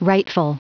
Prononciation du mot rightful en anglais (fichier audio)
Prononciation du mot : rightful